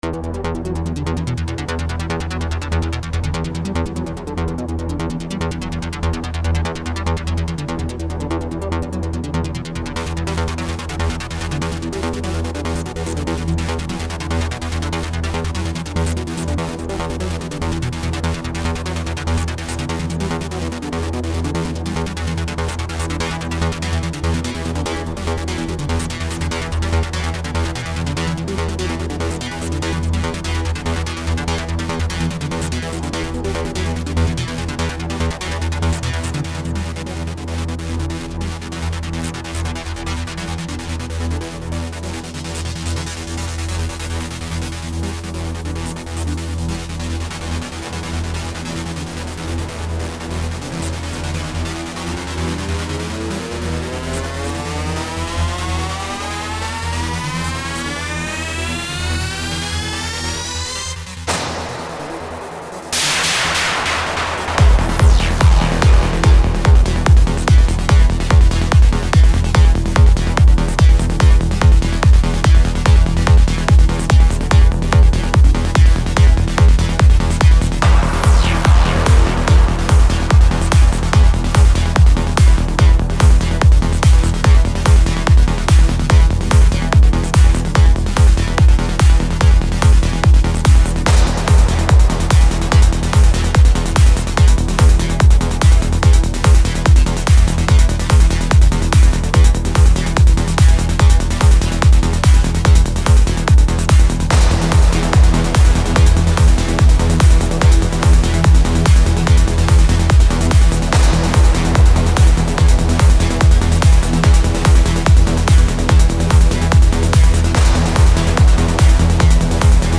אחי זה טוב ממש אולי קצת חוזר על עצמו הרבה בפתיחה וגם קצת בכללי
זה סטייל טראנס שנות השמונים לא?